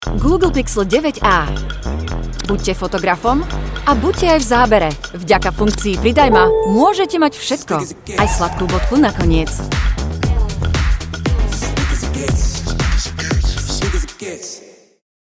Native speaker Female 20-30 lat
Native Slovak voice artist with a young, fresh female voice.
Spot reklamowy